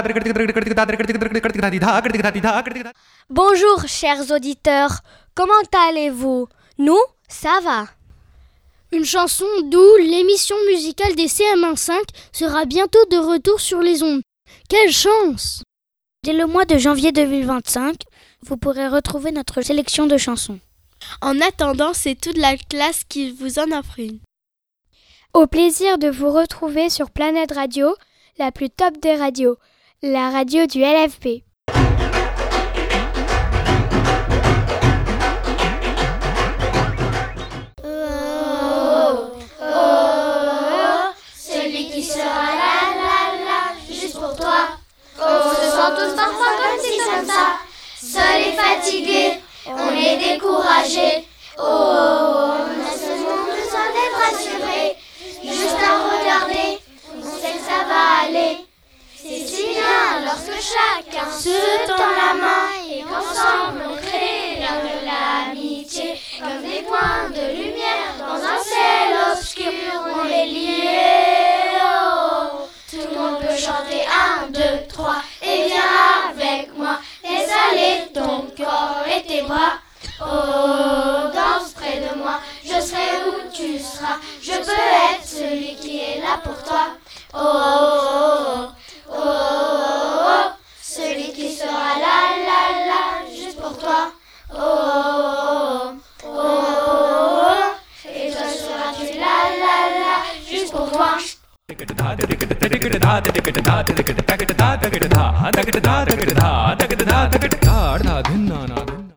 toute la classe